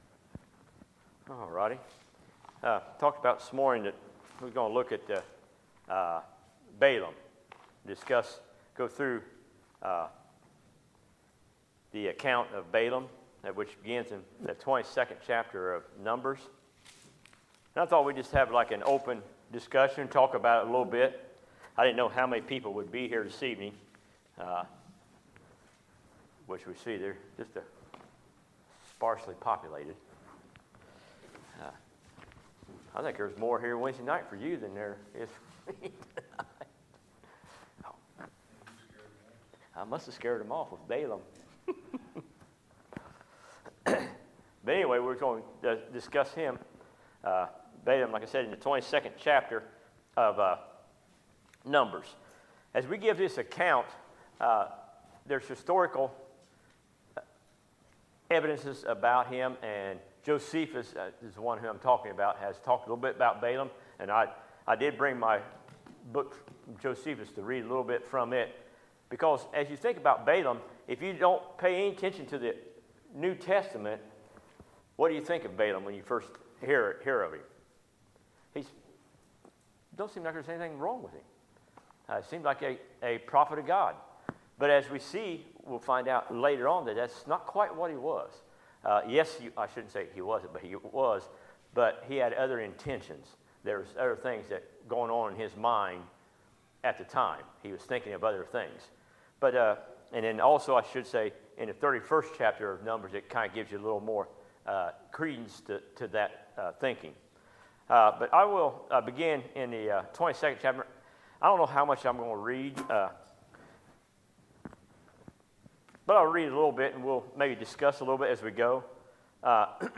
Sermons, November 4, 2018